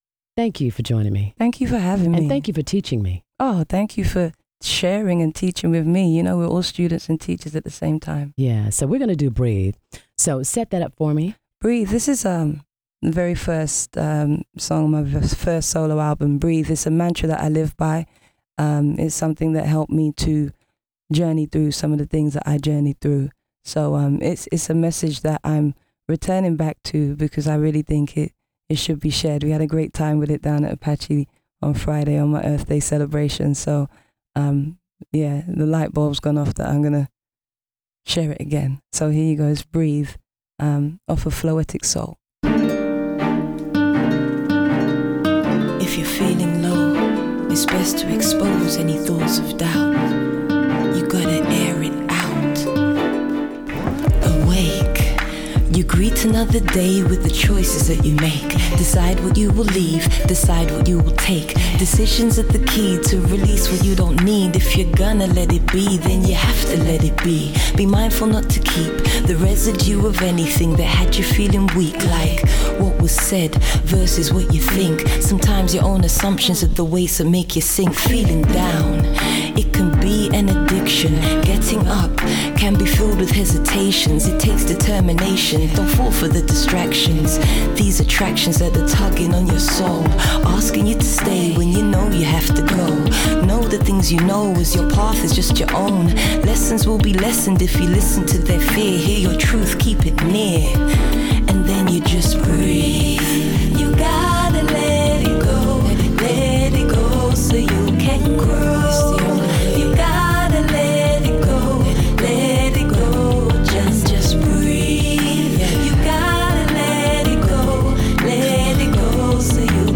Natalie Stewart “the Floacist” was live on the Sunday Brunch hear what she has to say about being creative.